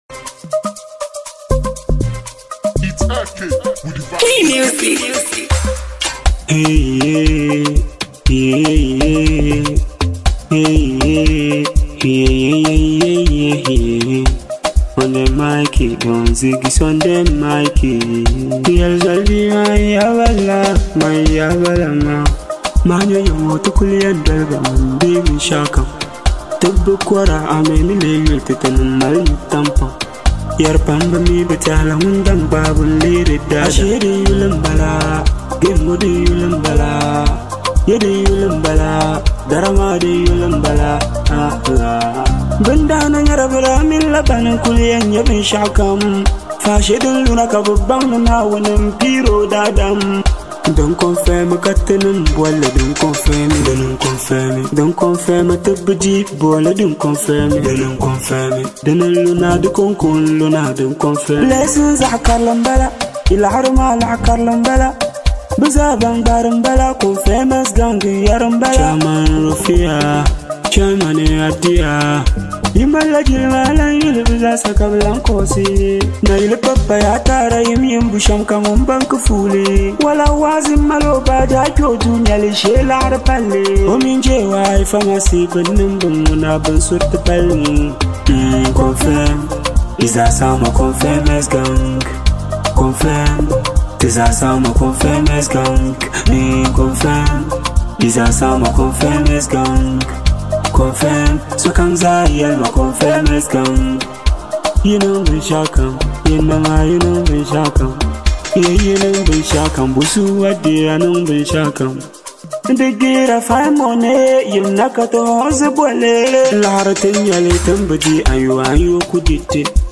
turns up the energy
With its catchy vibe and powerful message